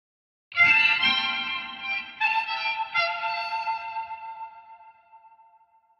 描述：男人试图像女孩一样哭的声音
标签： 伤心
声道立体声